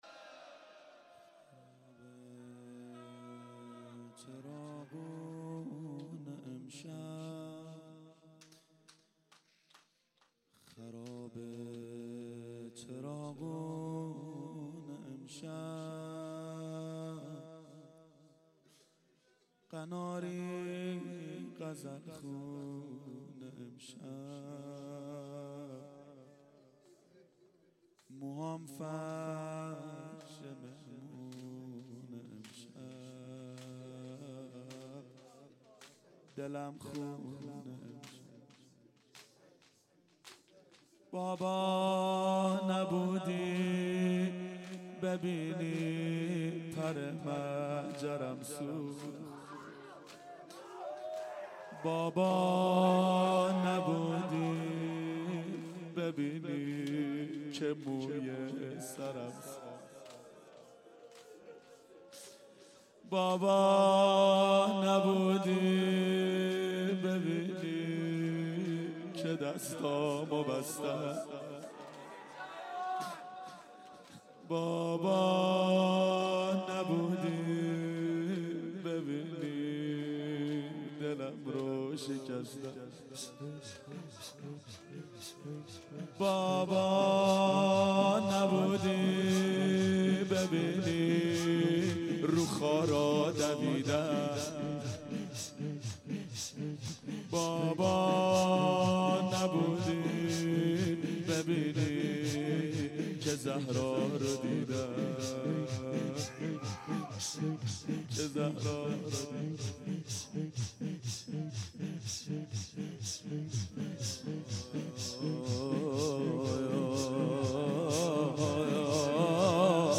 • دهه اول صفر سال 1392 هیئت شیفتگان حضرت رقیه سلام الله علیها